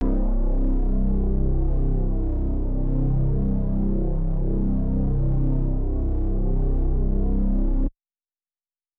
MOOG ONE SHOT BASS.wav